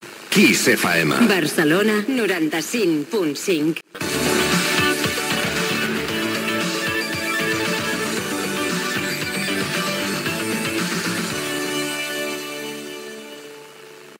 Indicatiu de la ràdio i freqüència a Barcelona